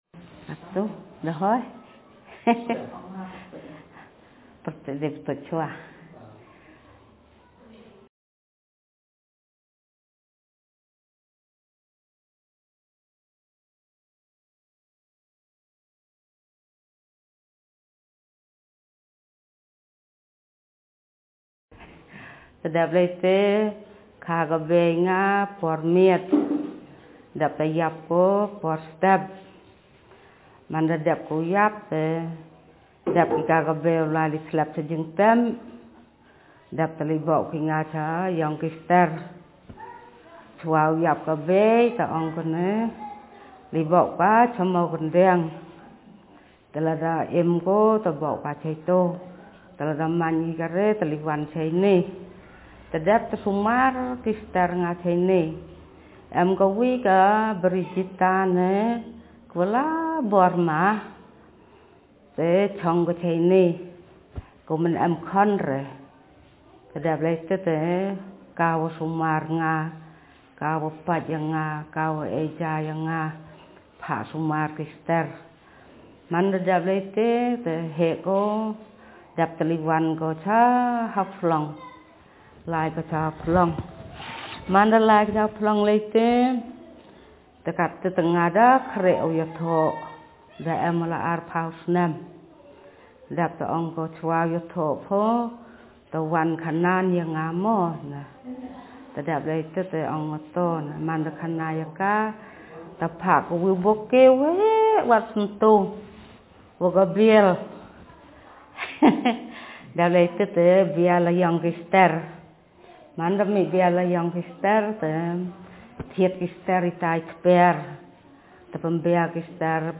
Speaker sex f Text genre personal narrative